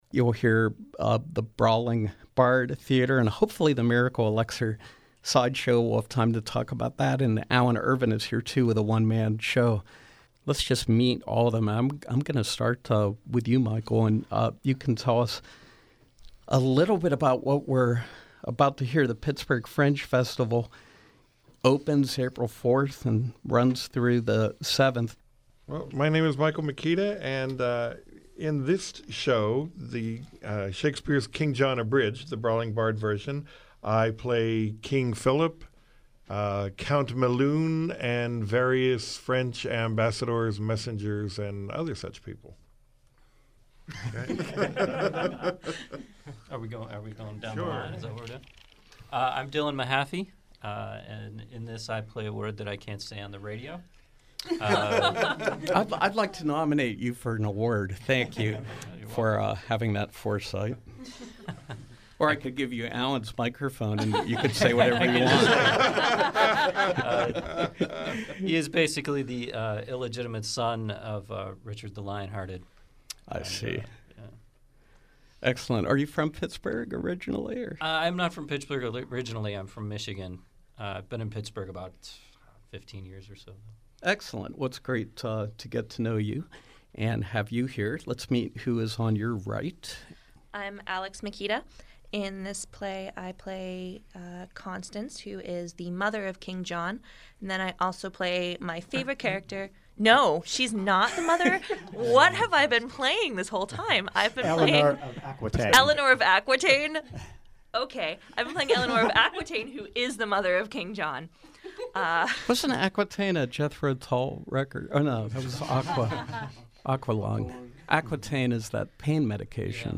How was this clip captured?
Live Performance: Pittsburgh Fringe Festival preview